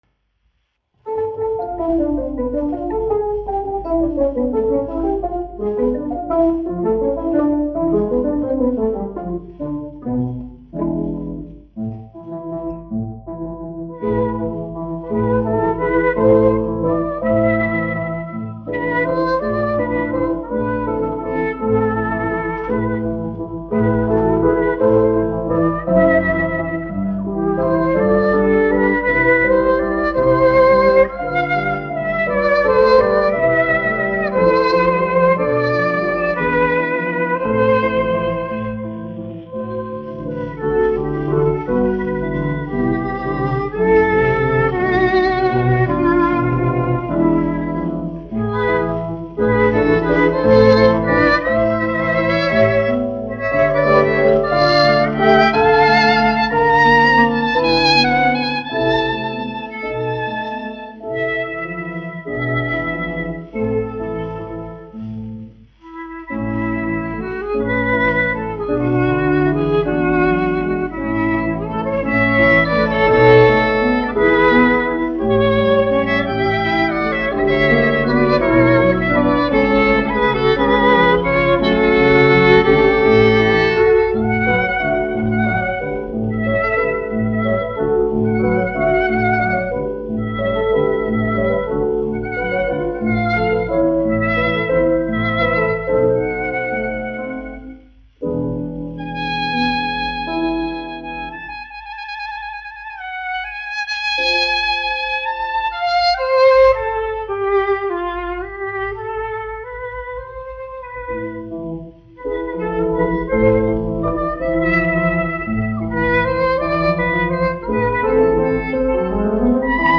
1 skpl. : analogs, 78 apgr/min, mono ; 25 cm
Vijoles un klavieru mūzika
Latvijas vēsturiskie šellaka skaņuplašu ieraksti (Kolekcija)